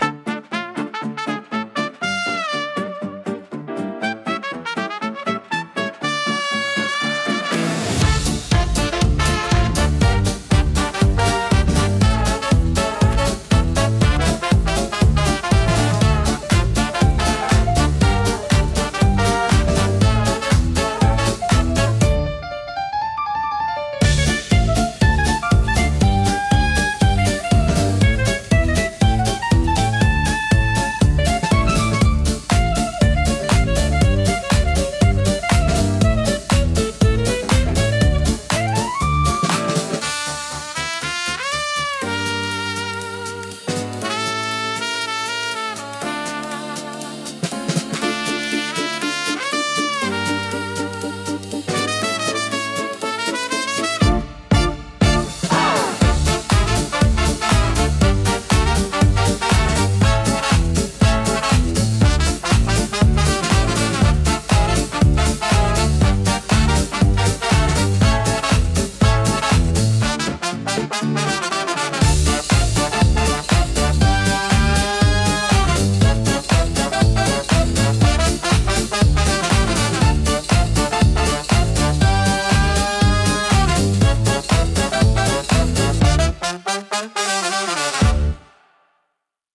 1930年代のクラシックなジャズの響きに、現代的なエレクトロビートが見事に融合。
トランペットやクラリネットのパンチの効いた音色が、スタイリッシュでエネルギーに満ちた空間を作り出します。
ポイントは、使い勝手抜群の歌なし（インストゥルメンタル）であること。